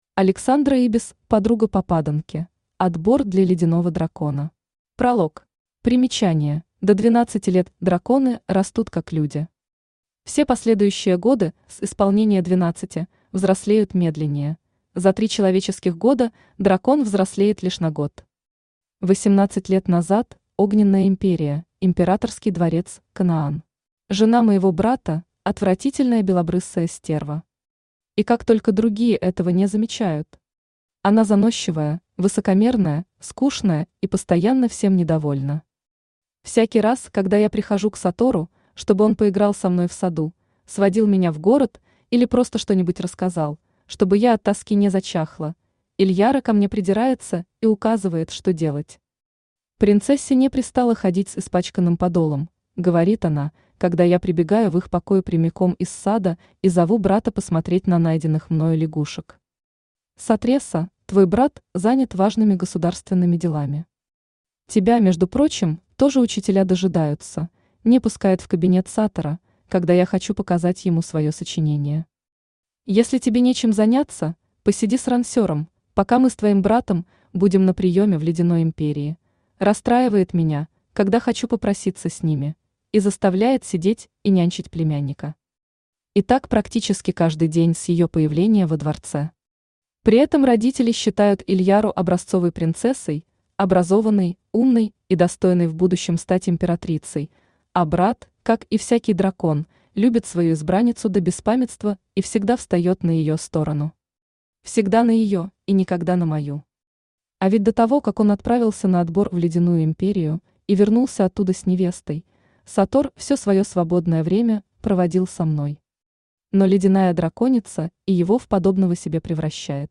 Аудиокнига Подруга попаданки: отбор для ледяного дракона | Библиотека аудиокниг
Aудиокнига Подруга попаданки: отбор для ледяного дракона Автор Александра Ибис Читает аудиокнигу Авточтец ЛитРес.